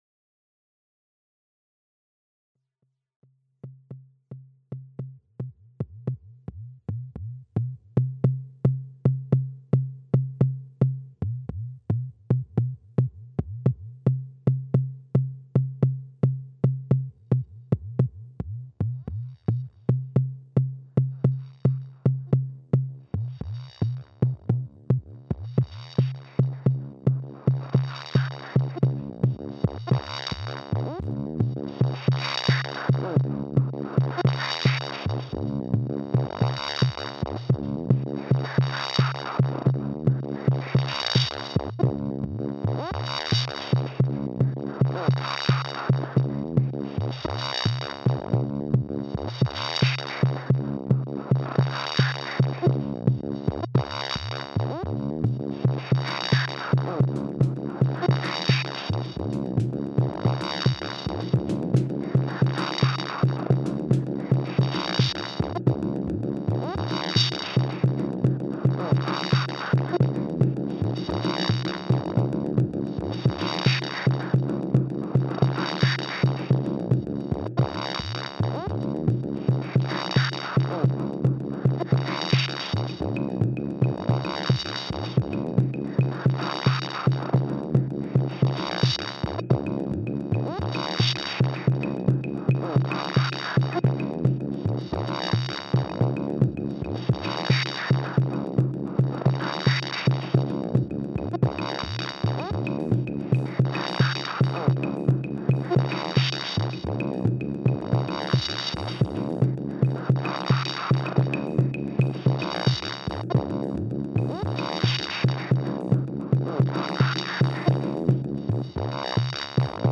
it's another live in one-pass recording thingy.